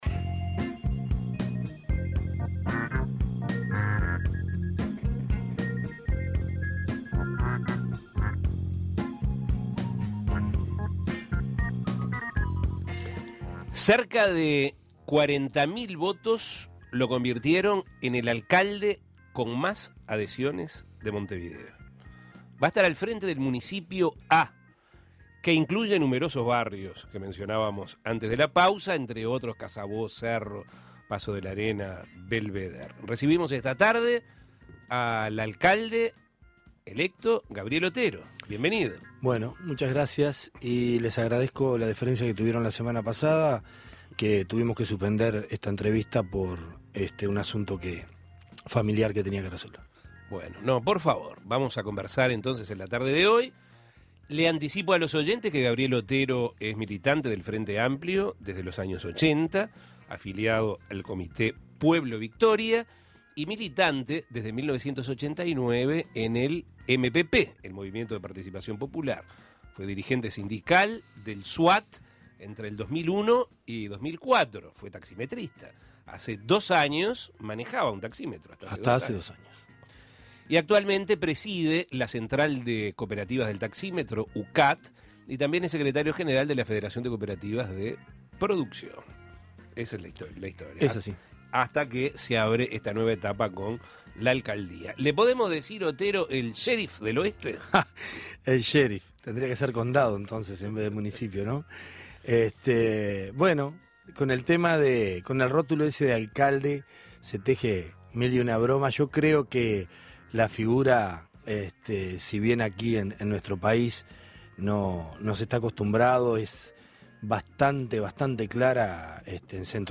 Dialogó, entre otras cosas, sobre lo que será su nueva actividad, que lo pondrá al frente del Municipio "A", donde se encuentran barrios como Casabó, La Teja, Cerro y Paso Molino. Escuche la entrevista.